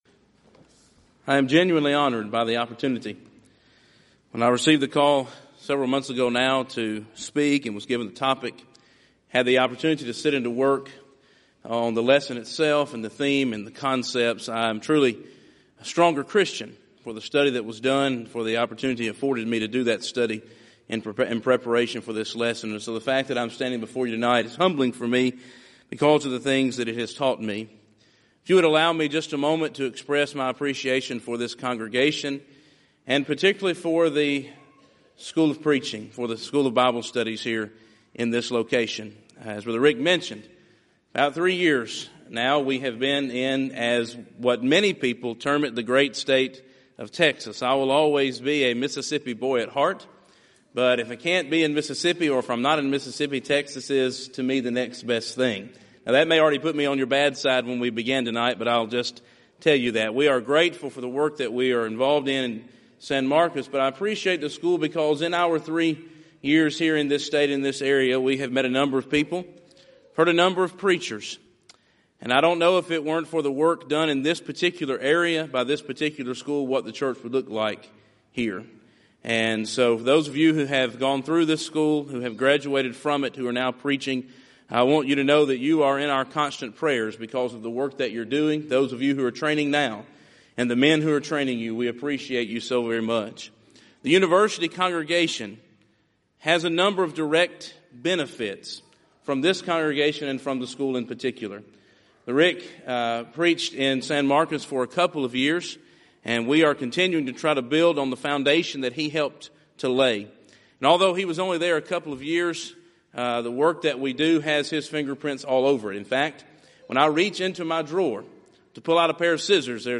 Event: 29th Annual Southwest Lectures Theme/Title: Proclaiming Christ: Called Unto Salvation